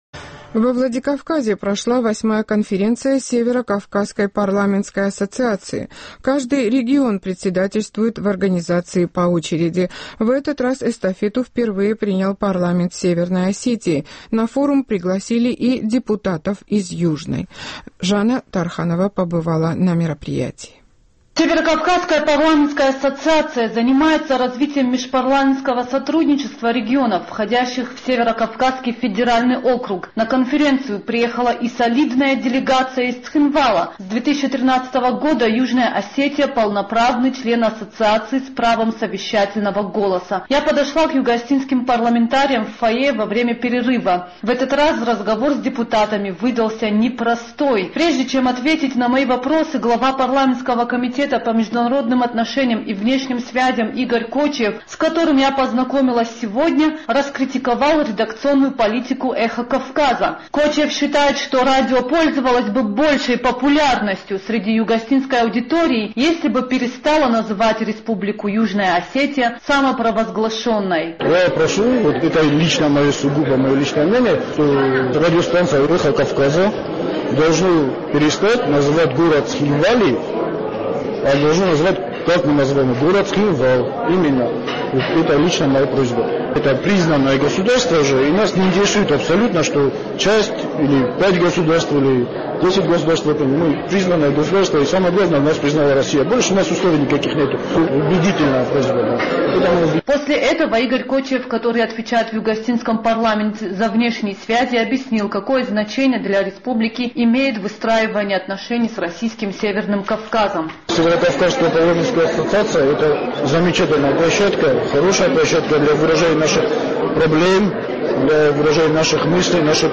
Я подошла к югоосетинским парламентариям в фойе во время перерыва.